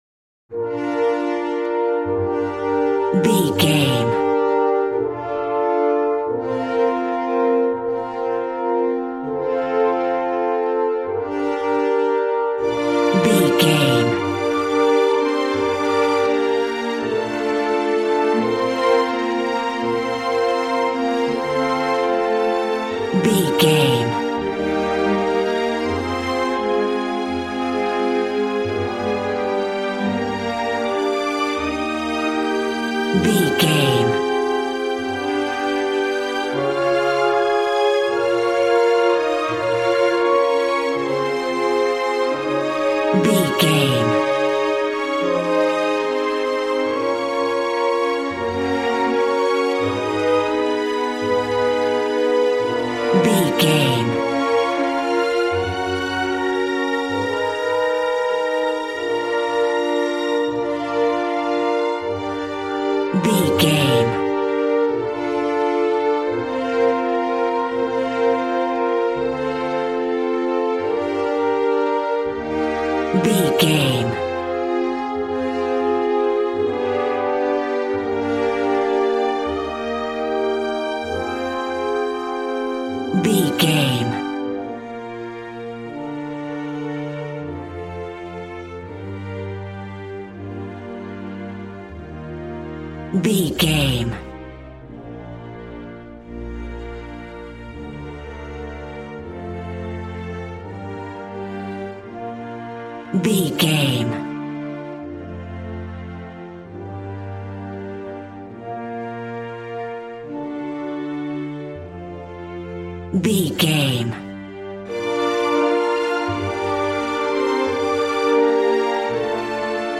Regal and romantic, a classy piece of classical music.
Ionian/Major
B♭
cello
violin
strings